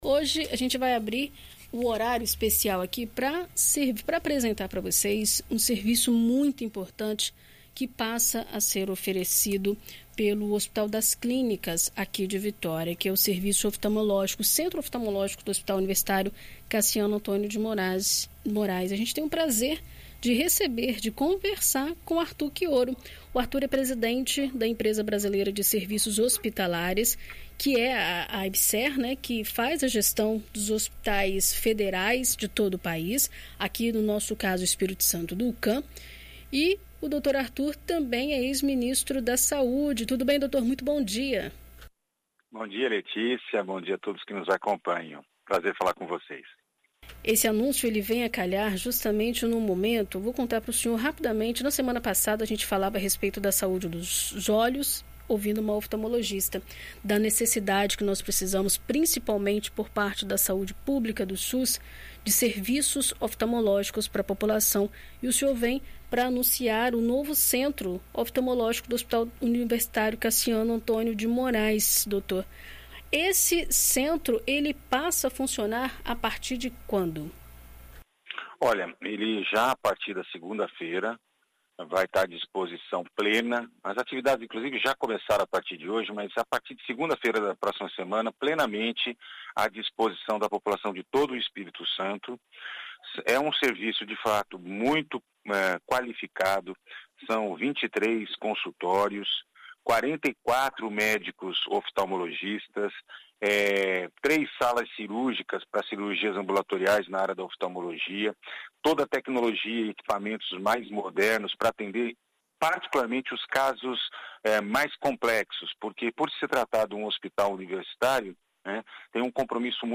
Em entrevista à BandNews FM ES nesta quinta-feira (10), o ex-ministro da Saúde e presidente da Empresa Brasileira de Serviços Hospitalares (Ebserh), Arthur Chioro, fala sobre as novas instalações do Hucam.